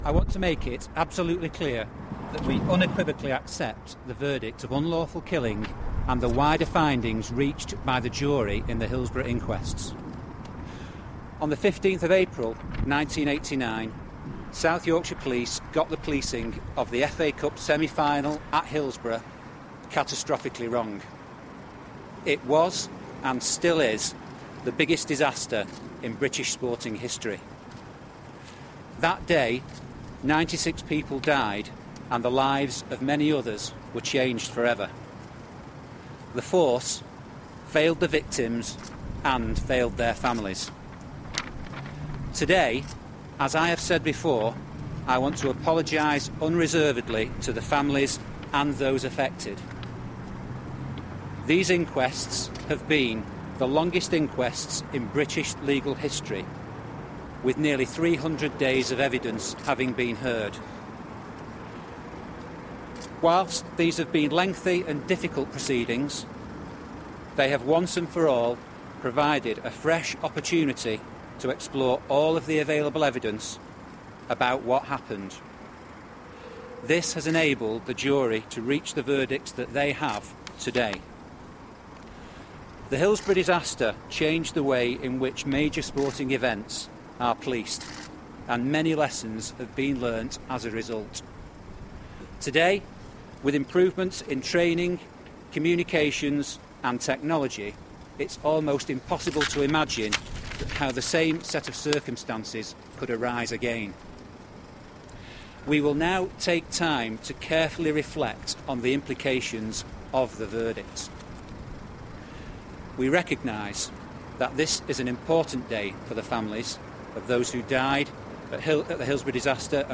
South Yorkshire Police Chief Constable Statement